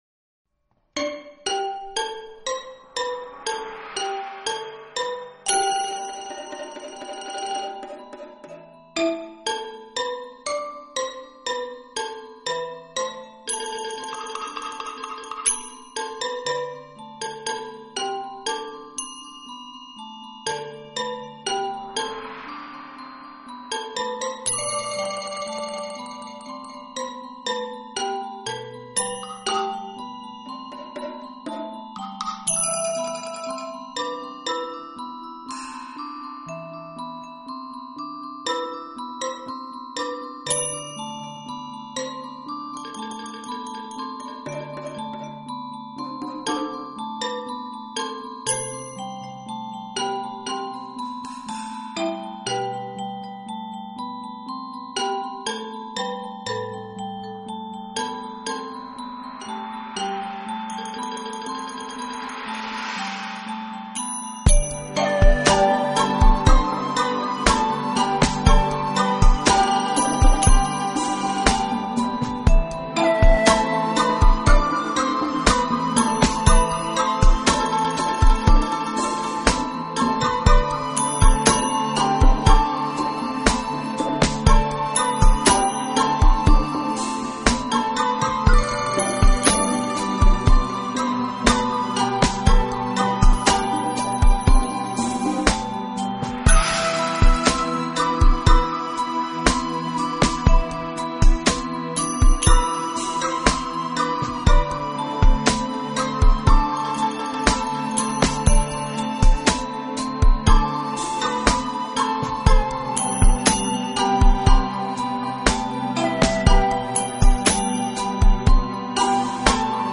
专辑类型: Lo-Fi
试听曲是中国风格的，不知作曲家想